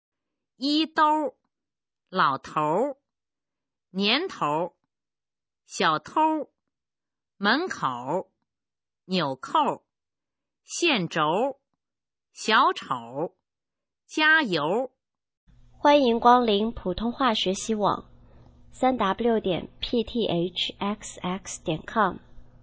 首页 视听 学说普通话 儿化词语表
普通话水平测试用儿化词语表示范读音第21部分